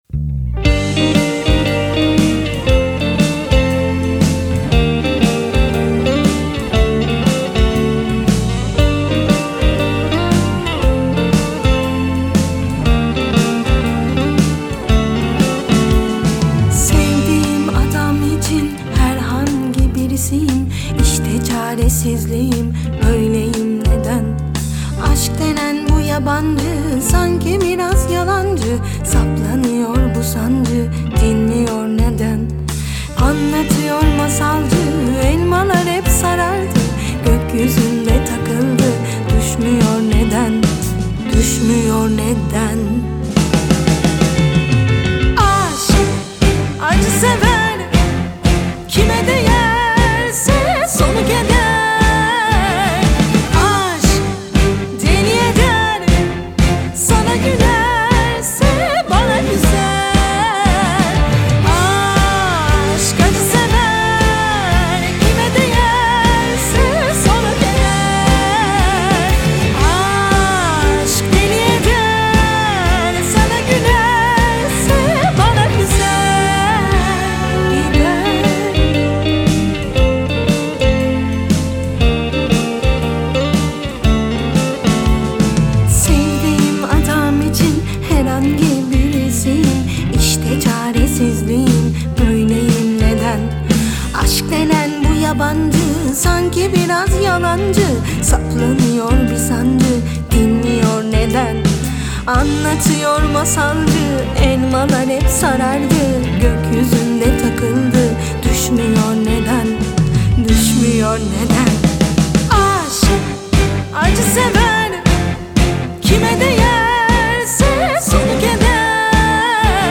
آهنگ تورکی
جزو بهترین آهنگ های راک تورکی